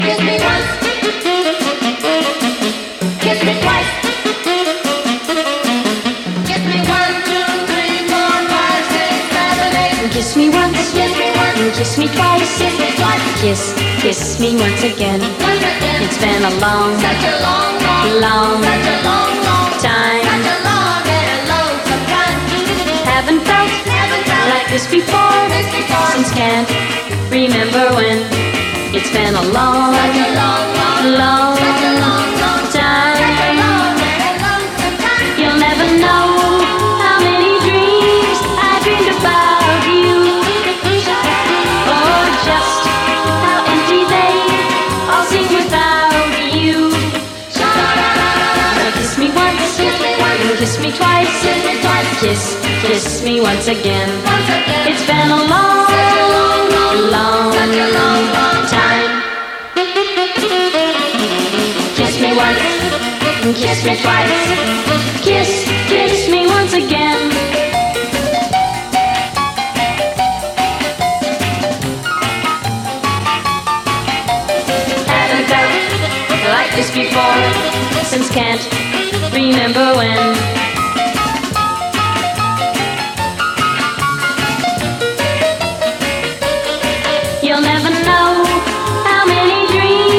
EASY LISTENING / OTHER / CHORUS / OLDIES / GIRL POP